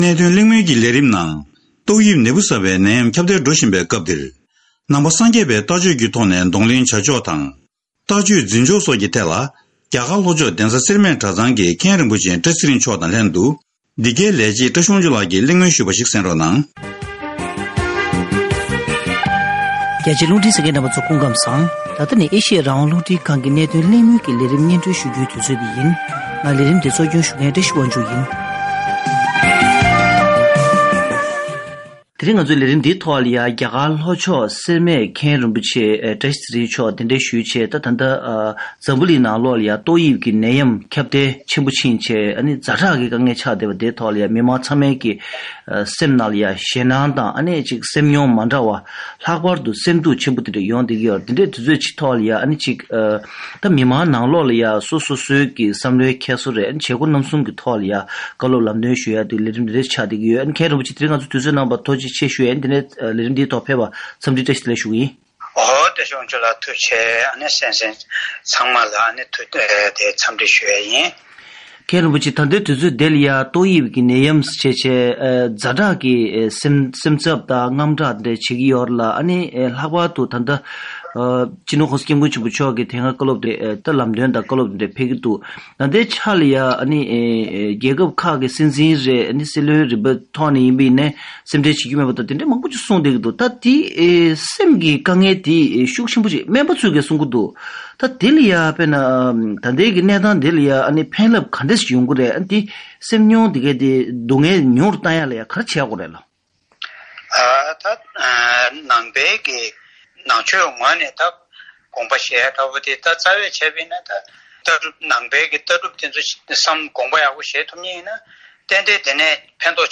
ཐེངས་འདིའི་གནད་དོན་གླེང་མོལ་གྱི་ལས་རིམ་ནང་དུ